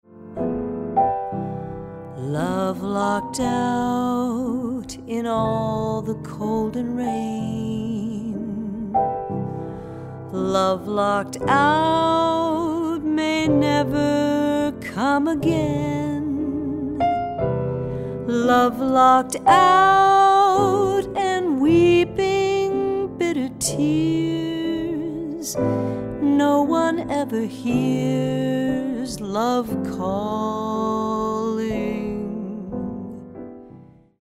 vocals, guitar
trumpet, flugelhorn
piano
bass
drums